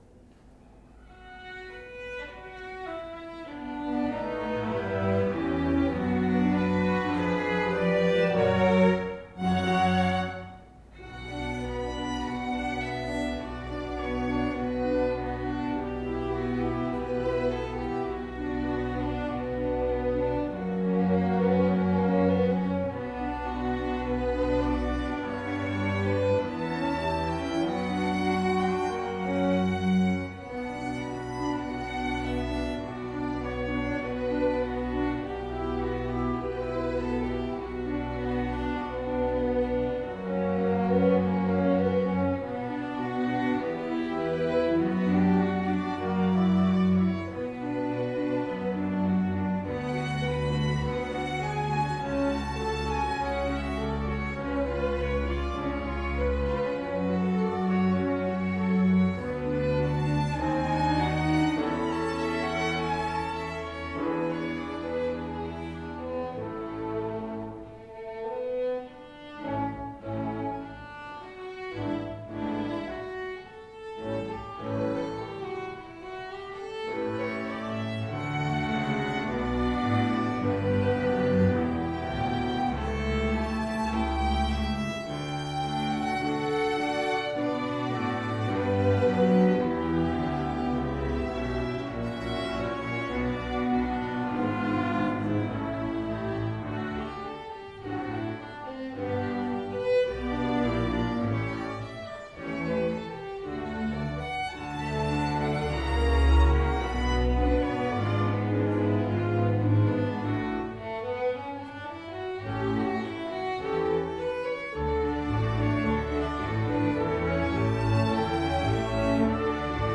Concerto per violino ed orchestra in si minore, primo movimento di Oskar Rieding
violino
Dal Concerto del 16 ottobre 2016